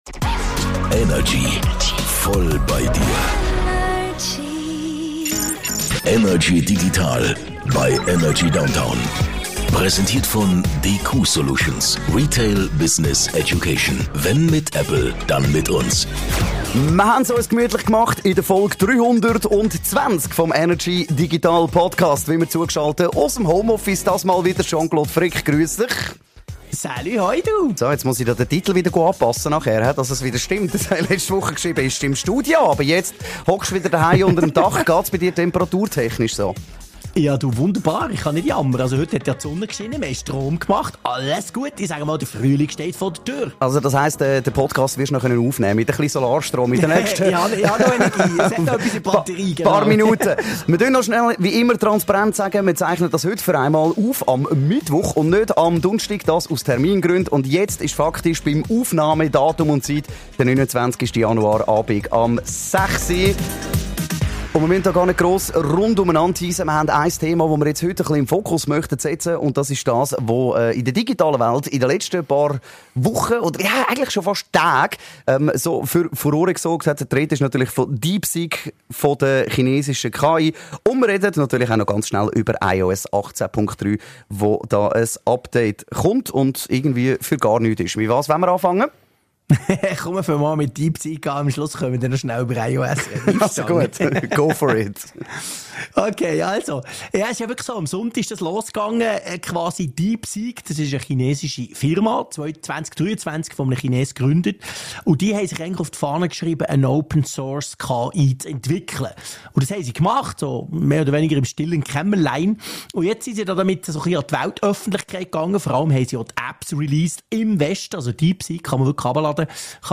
im Energy Studio
aus dem HomeOffice